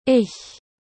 • Le « ch » doux.
Dans ce cas-là, le son h est un mixte entre le h aspiré et le « sch ».